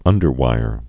(ŭndər-wīr)